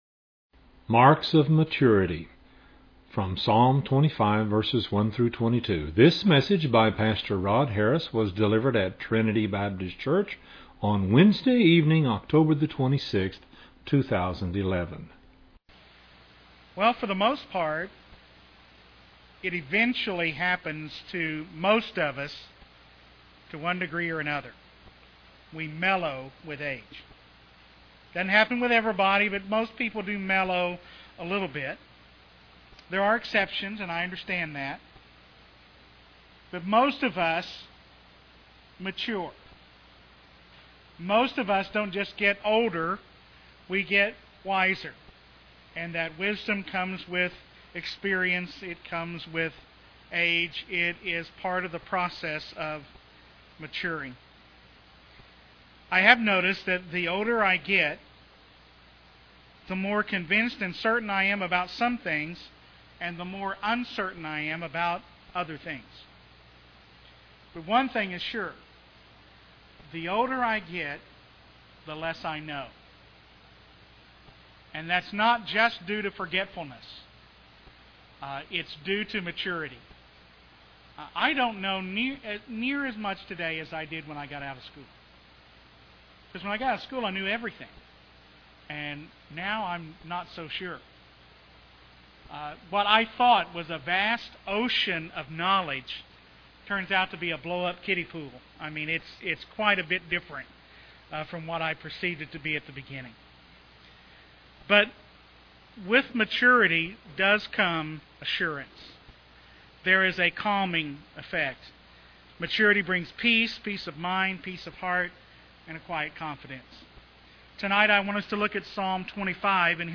An exposition of Psalm 25:1-22.
delivered at Trinity Baptist Church on Wednesday evening, October 26, 2011.